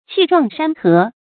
注音：ㄑㄧˋ ㄓㄨㄤˋ ㄕㄢ ㄏㄜˊ
氣壯山河的讀法